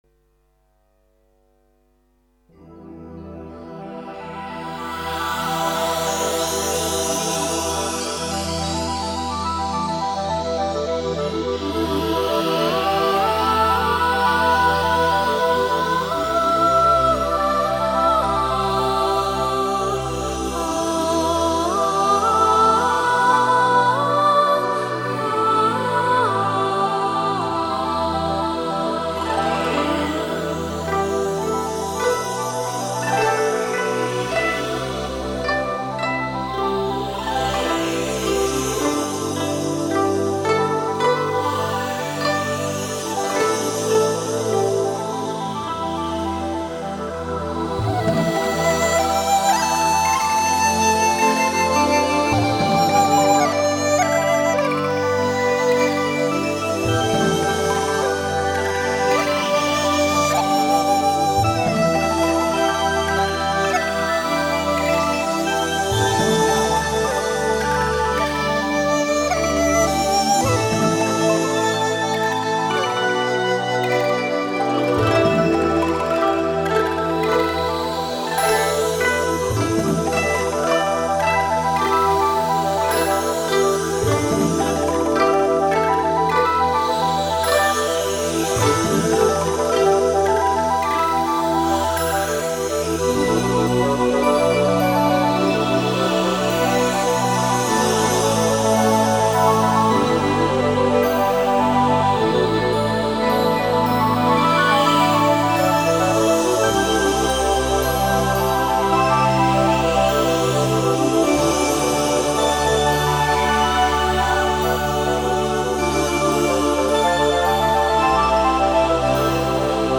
2周前 纯音乐 8